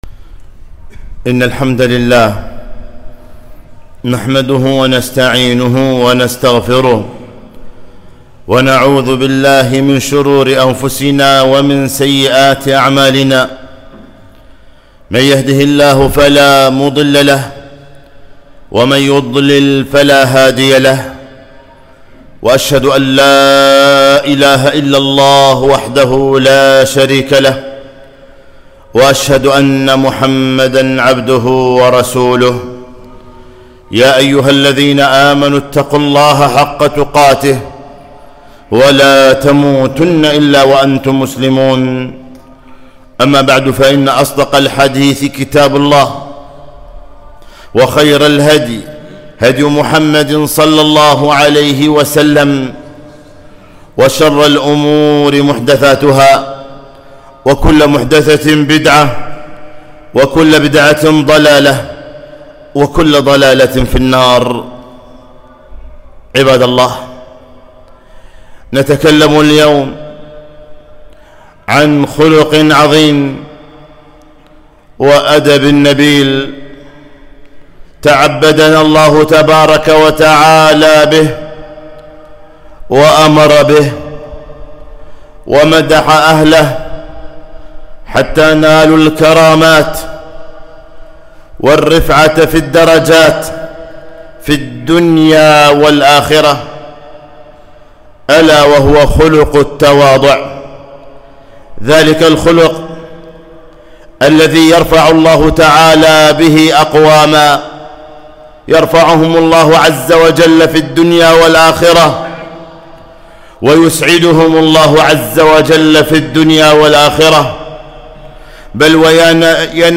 خطبة - من تواضع لله رفعه